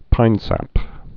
(pīnsăp)